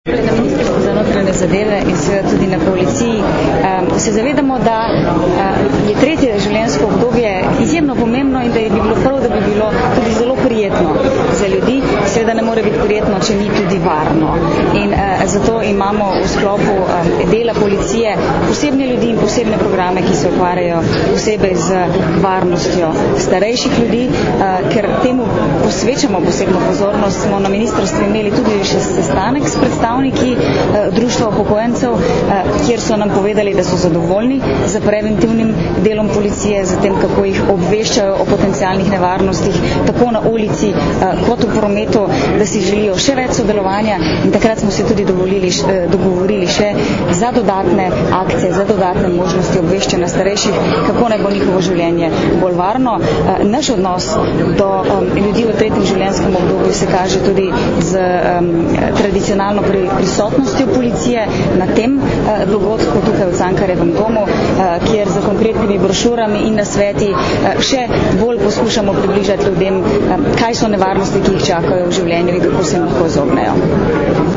Izjava ministrice za notranje zadeve Katarine Kresal (mp3)